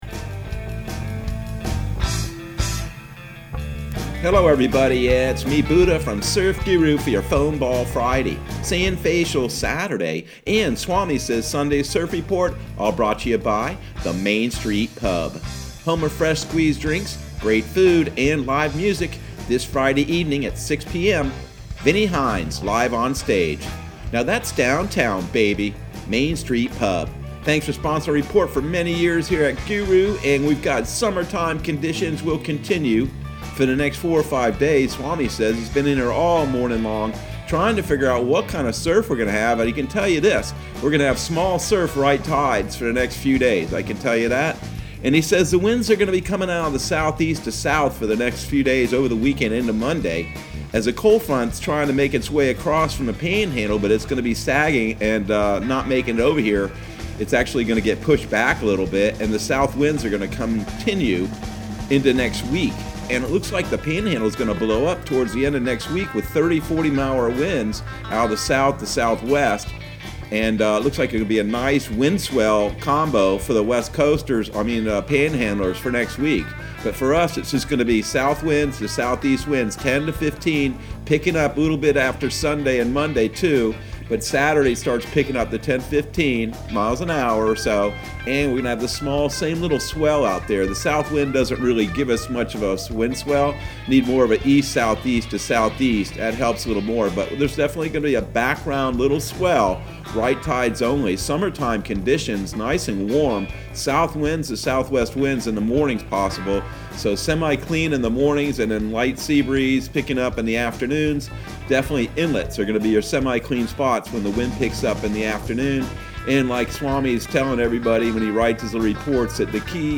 Surf Guru Surf Report and Forecast 02/12/2021 Audio surf report and surf forecast on February 12 for Central Florida and the Southeast.